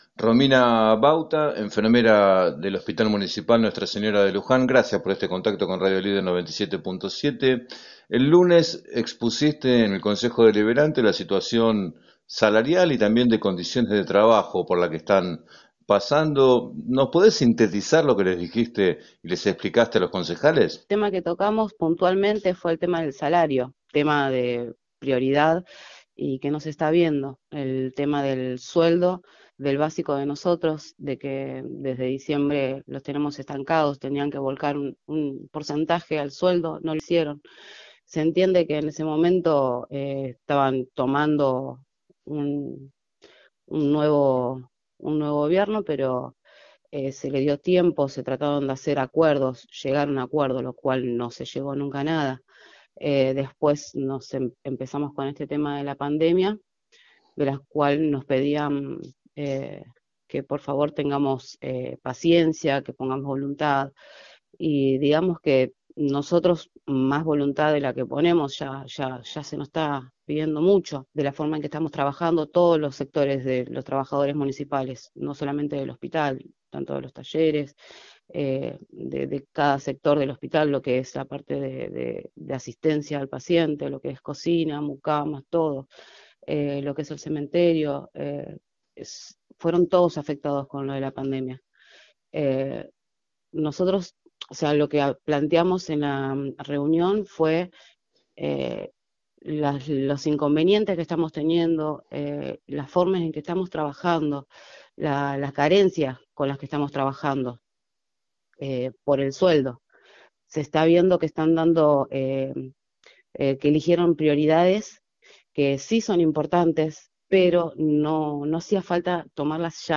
En diálogo con Radio Líder 97.7